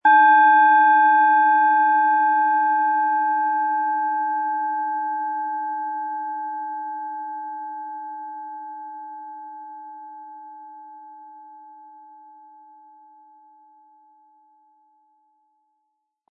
Planetenton 1
Sie möchten den schönen Klang dieser Schale hören? Spielen Sie bitte den Originalklang im Sound-Player - Jetzt reinhören ab.
Aber dann würde der kraftvolle Klang und das einzigartige, bewegende Schwingen der traditionsreichen Herstellung fehlen.
Mit einem sanften Anspiel "zaubern" Sie aus der Saturn mit dem beigelegten Klöppel harmonische Töne.
MaterialBronze